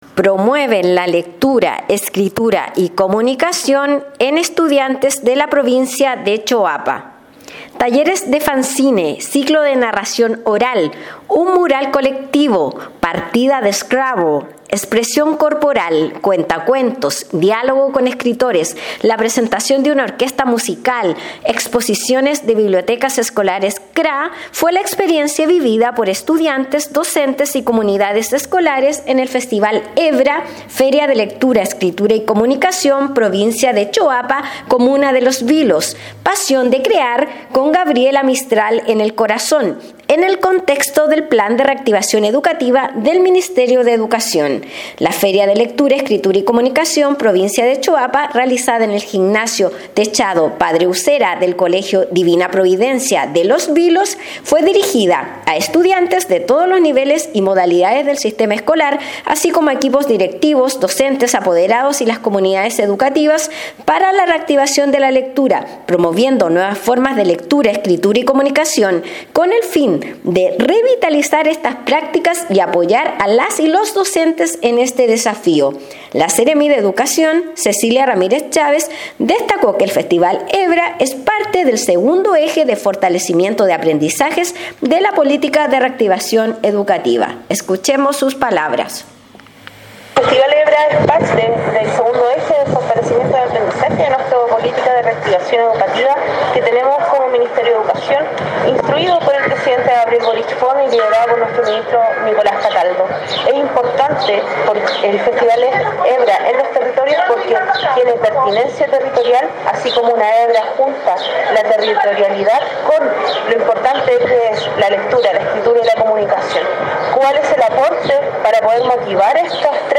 Despacho-Radial-Promueven-la-lectura-escritura-y-comunicacion-en-estudiantes-de-la-Provincia-de-Choapa_.mp3